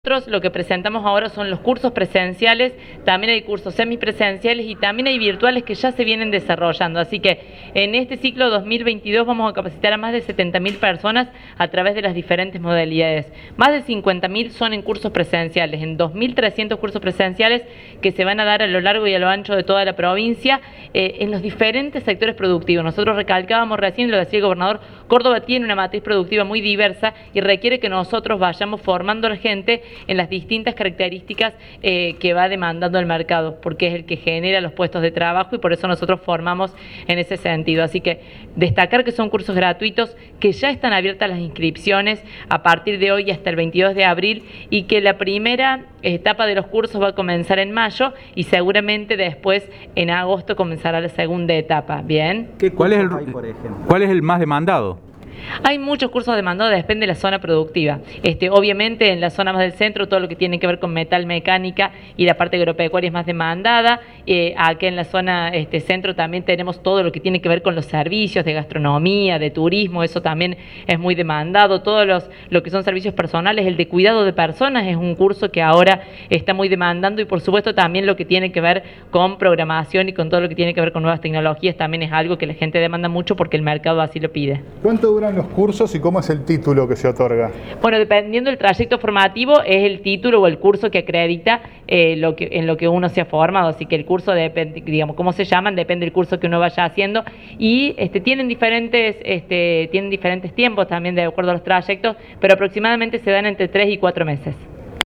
Audio: Laura Jure (ministra de Promoción del Empleo y de la Economía Familiar).